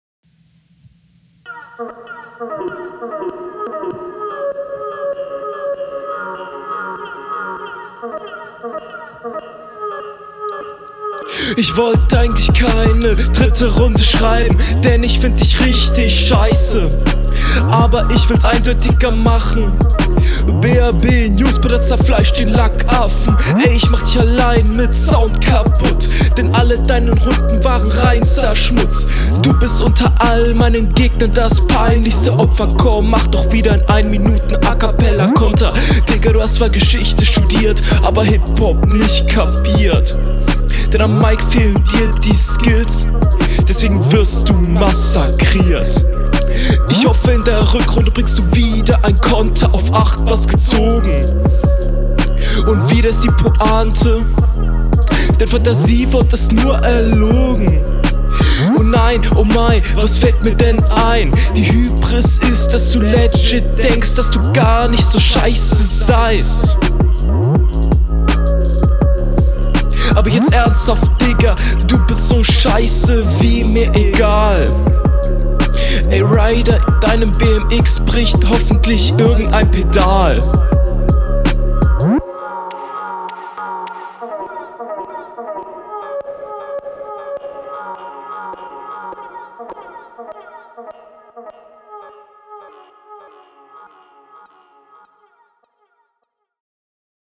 Beat ist dope, aber er kommt nich so krass auf dem Beat.